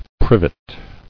[priv·et]